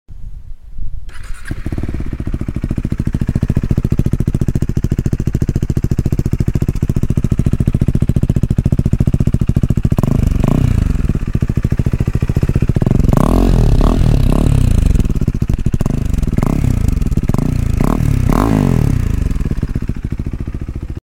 Pure sound KTM full exhaust sound effects free download